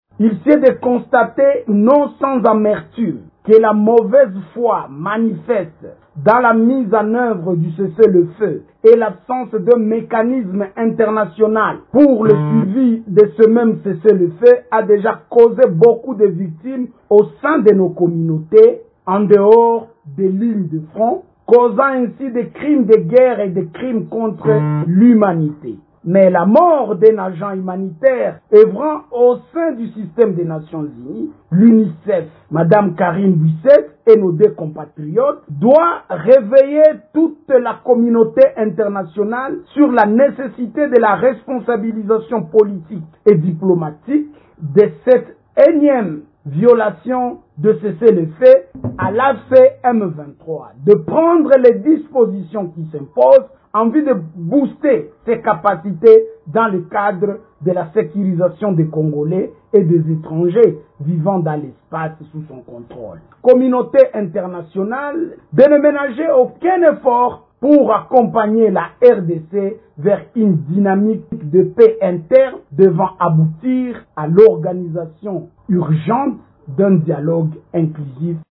Les signataires insistent sur l’urgence d’un dialogue interne et l’obligation pour toutes les parties de respecter le cessez-le-feu. On écoute un extrait de cette déclaration lu par